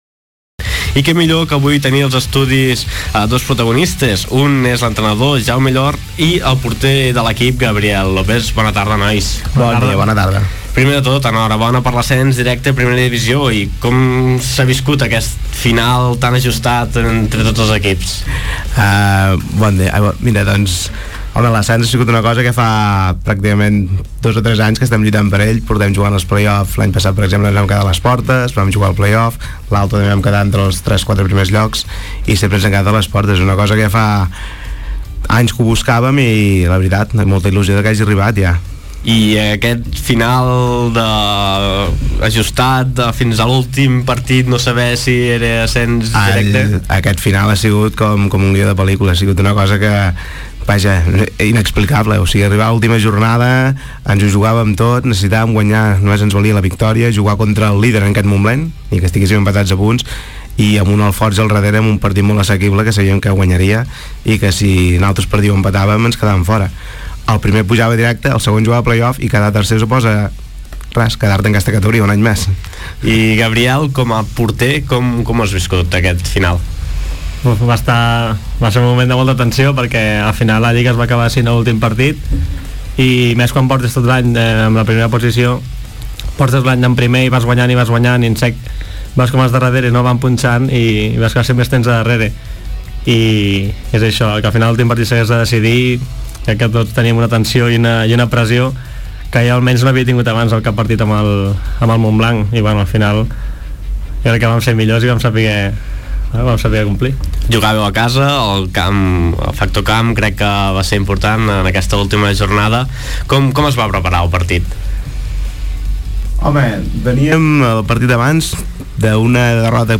Entrevista-infoesport.mp3